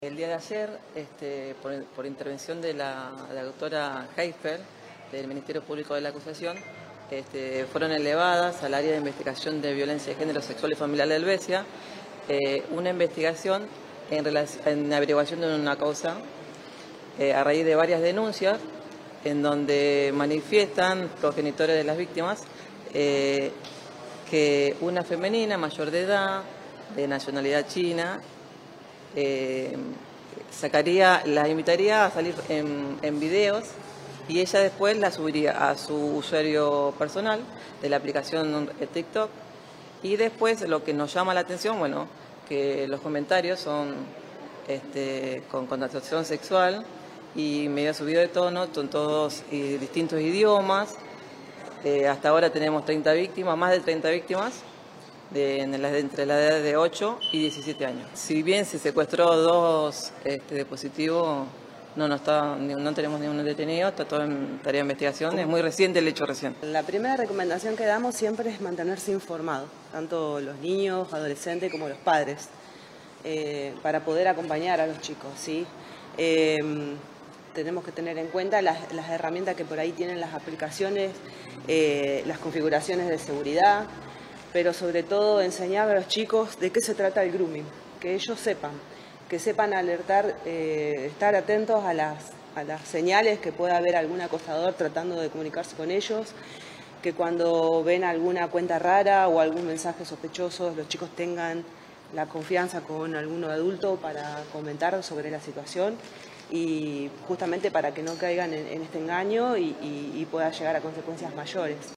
Durante la conferencia de prensa